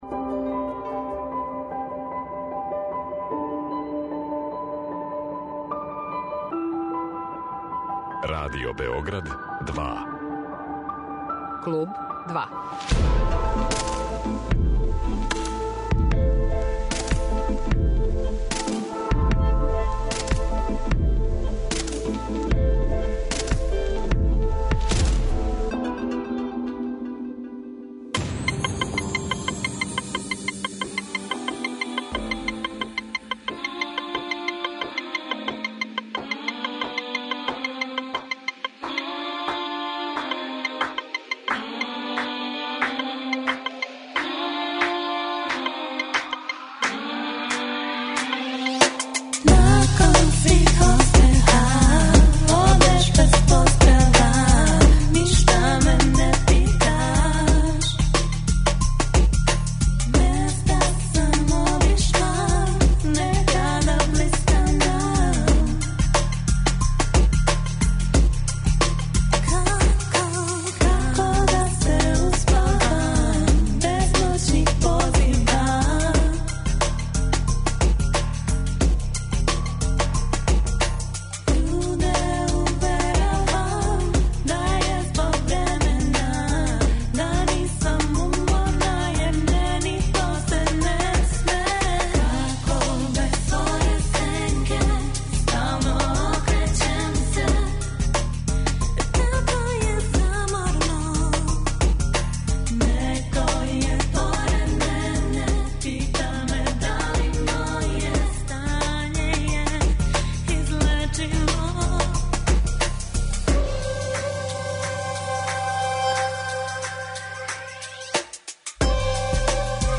Женски дуо Ања Штарк представља једно од освежавајућих појава на српској сцени. Електро поп звук и интересантни текстови, зачињени адекватним музичким амбијентом, чине овај бенд апсолутно неодољивим.